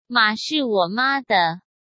Mǎ shì wǒ mā de.